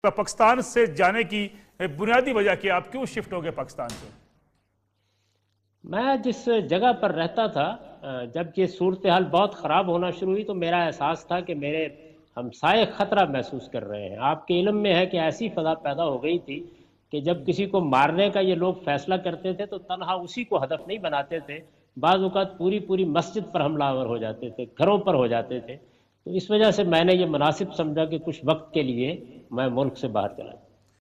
Category: TV Programs / Neo News /
In this program Javed Ahmad Ghamidi answer the question about "Why Mr. Ghamidi Left Pakistan" on Neo News.